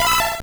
Cri d'Hypocéan dans Pokémon Or et Argent.